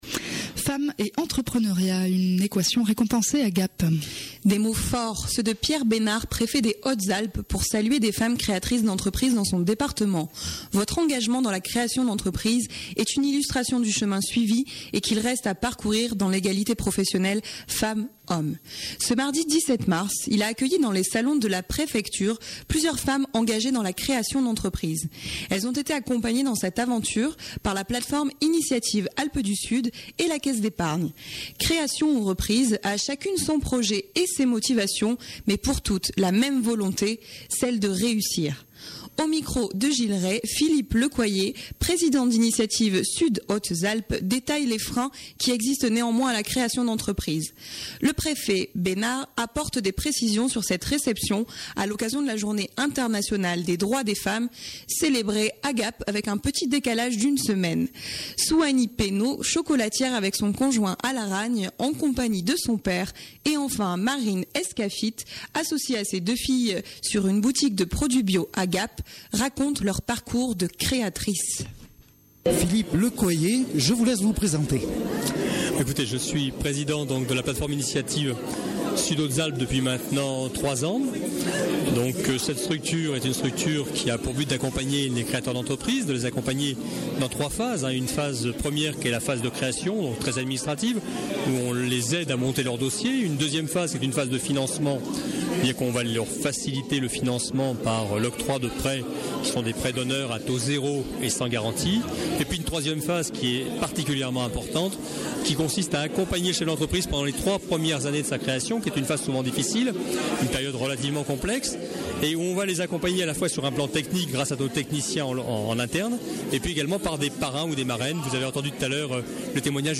Ce mardi 17 mars, il a accueilli dans les salons de la préfecture plusieurs femmes engagées dans la création d’entreprise.
Le Préfet Besnard apporte des précisions sur cette réception à l'occasion de la journée internationale des droits des femmes, célébrée avec un petit décalage d'une semaine.